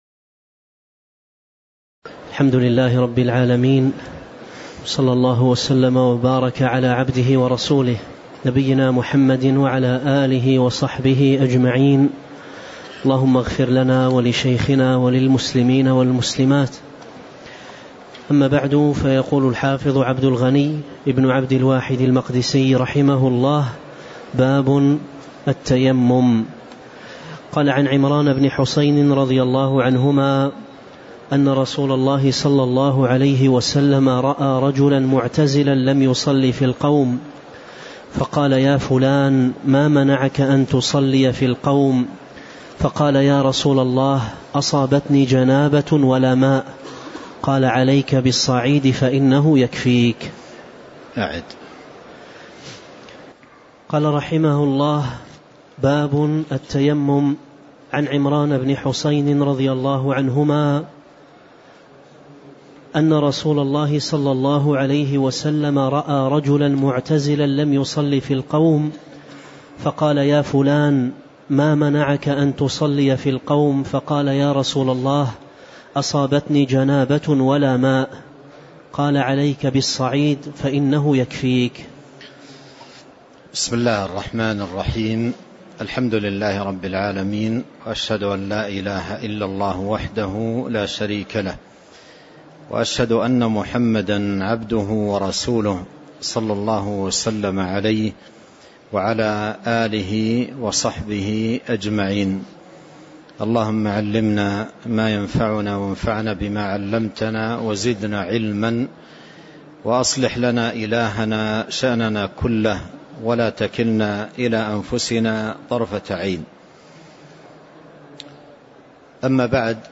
تاريخ النشر ١٦ ربيع الأول ١٤٤٤ هـ المكان: المسجد النبوي الشيخ